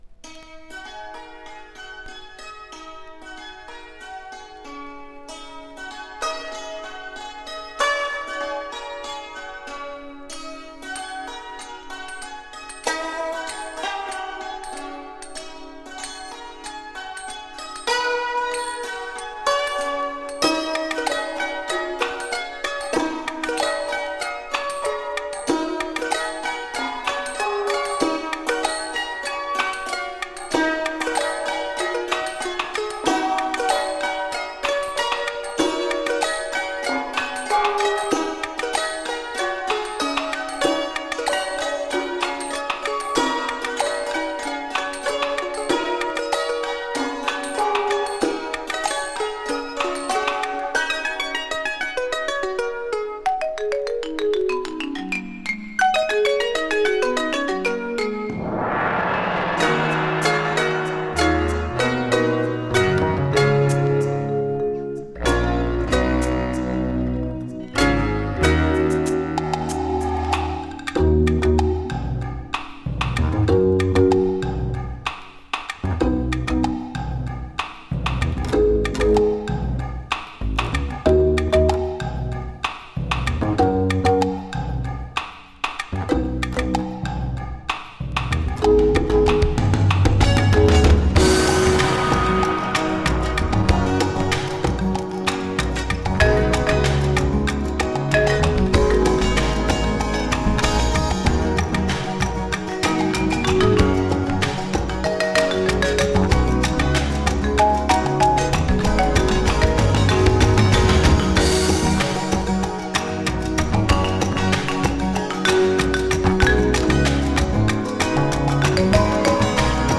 German new age fusion!
German guitarist and multiplayer.